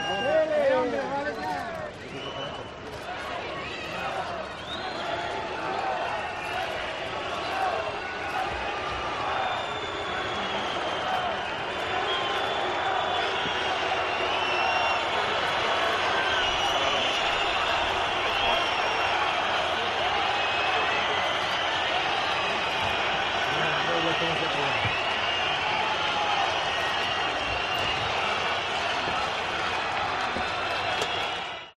Pitos y silbidos a Pedro Sánchez al principio y fin del desfile del Día de la Fiesta Nacional
El cambio de ubicación del desfile no ha evitado que el público abuchee a Pedro Sánchez